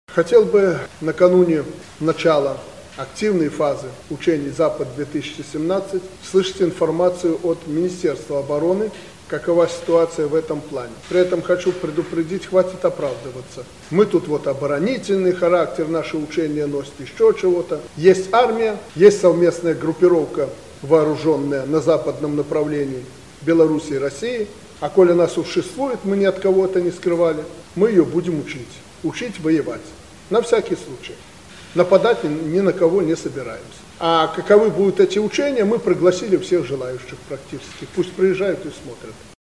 В этом смогут убедиться и зарубежные наблюдатели, заявил Президент Александр Лукашенко на встрече с руководителями силового блока.